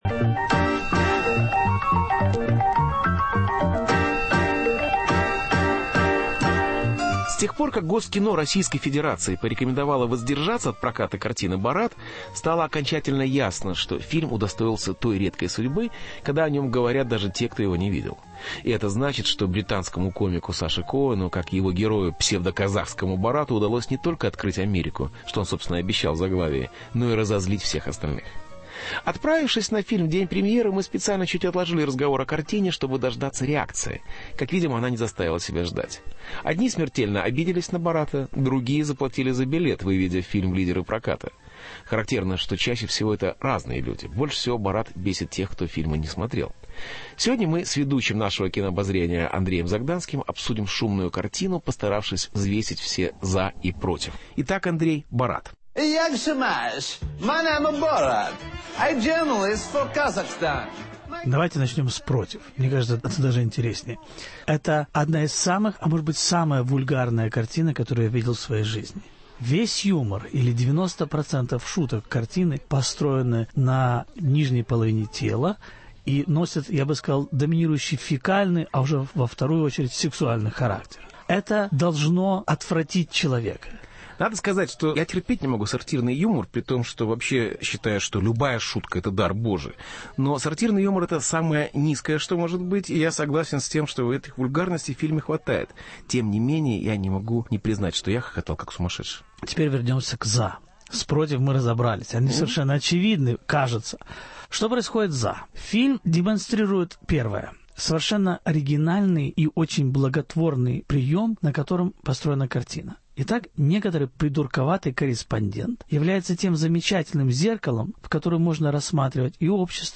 Дискуссия о скандальном фильме Саши Коэна.